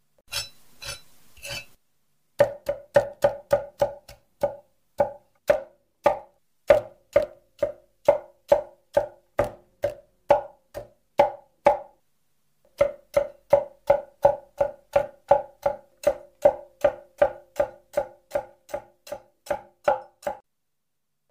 Tiếng Chặt Thịt, Đồ ăn… trên thớt (đều đặn, nhanh)
Thể loại: Tiếng ăn uống
Description: Âm thanh “cắc… cắc… cắc…” vang lên đều đặn, nhanh gọn, như nhịp trống dồn dập của bếp ăn. Tiếng dao bén chém xuống thớt, chặt thịt, thái rau, băm nhỏ nguyên liệu — sắc lẹm, giòn giã, vang vọng khắp gian bếp. Mỗi nhát chặt chuẩn xác, liên tiếp, tạo nên nhịp điệu rộn ràng, gợi mùi thơm và hơi nóng của món ăn đang chờ được chế biến.
tieng-chat-thit-do-an-tren-thot-deu-dan-nhanh-www_tiengdong_com.mp3